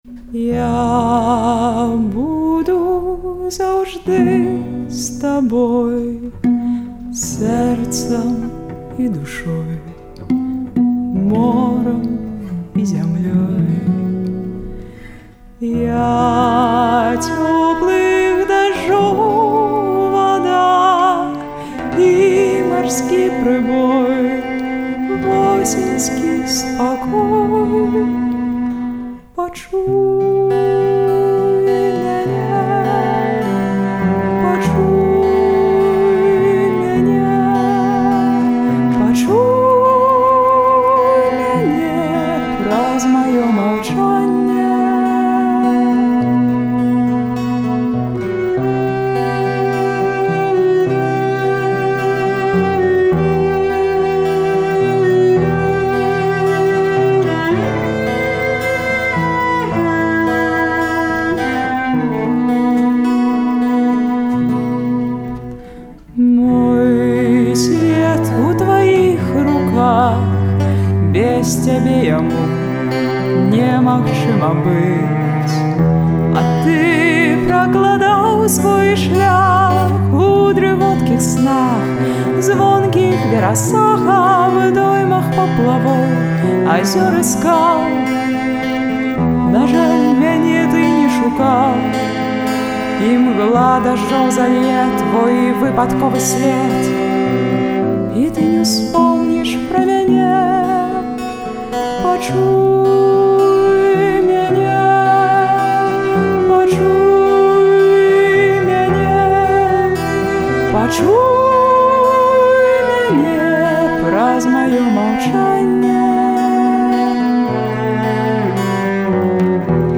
удзельніца «Бардаў Свабоды — 2012».